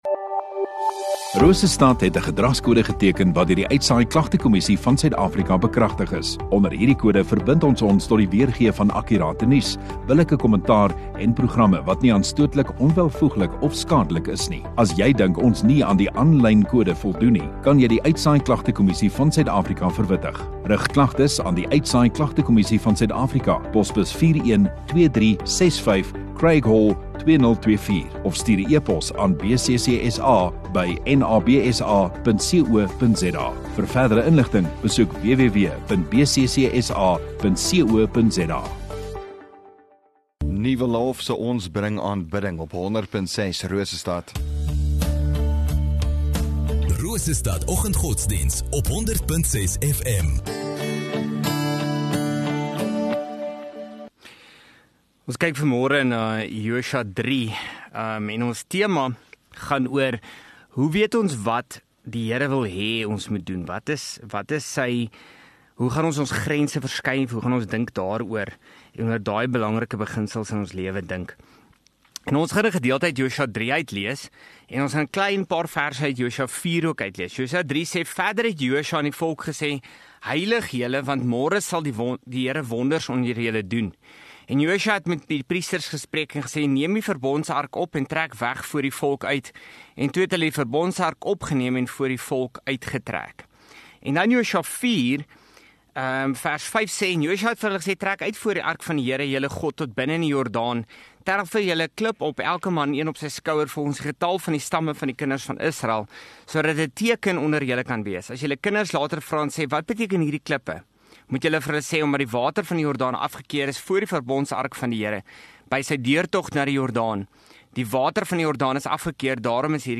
23 Feb Maandag Oggenddiens